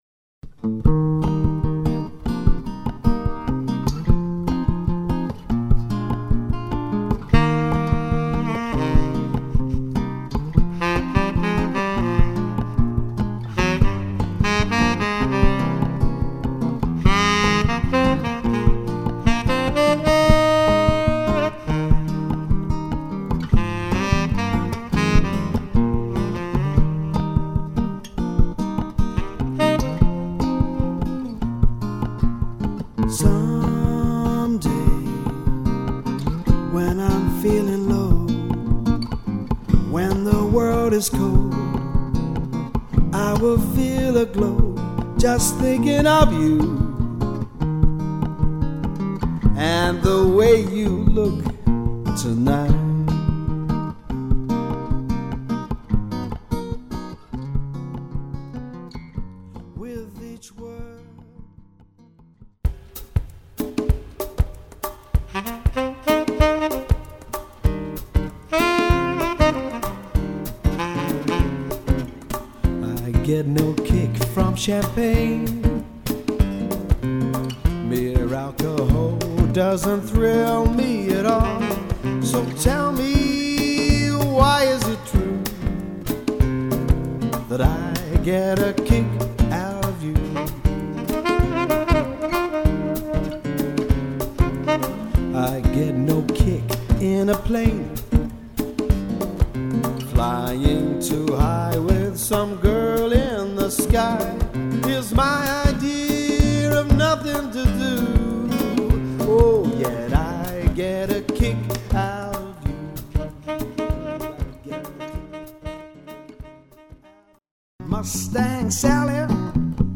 Guitar, Lead Vocals, Percussion
Saxophone, Percussion